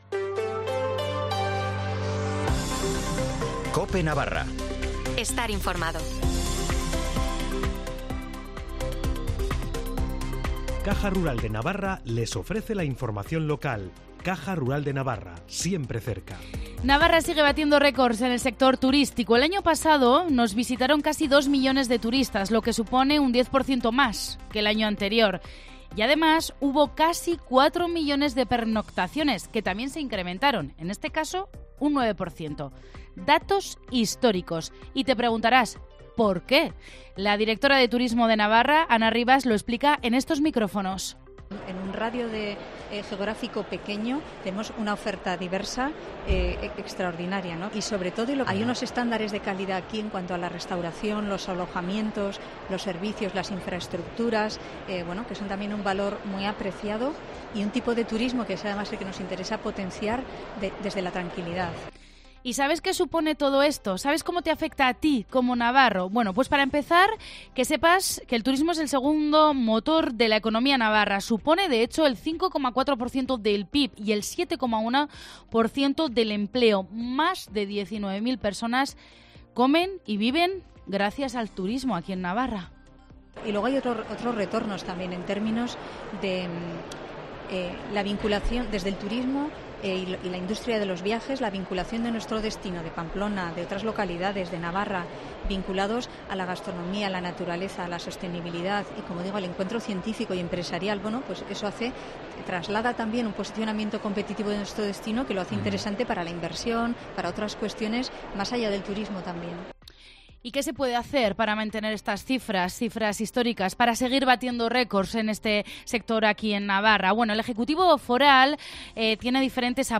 Directo desde la Feria de Turismo Navartur en Baluarte